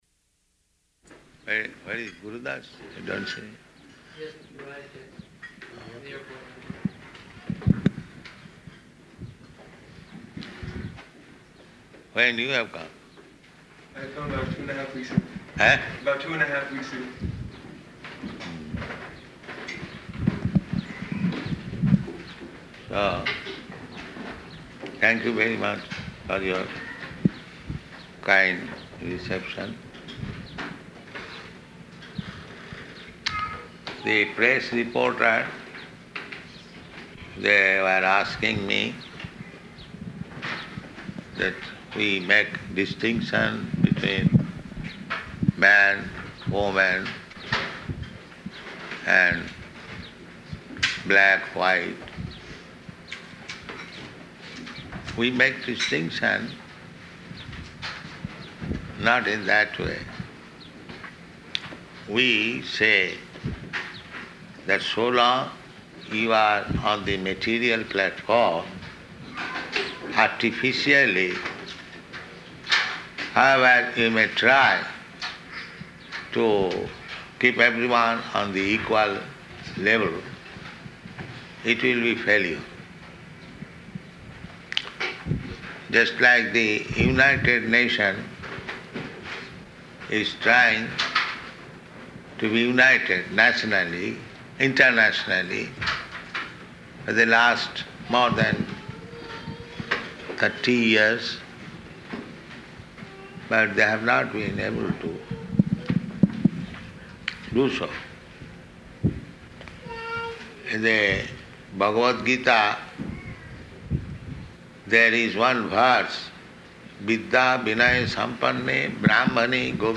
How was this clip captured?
-- Type: Lectures and Addresses Dated: July 11th 1975 Location: Philadelphia Audio file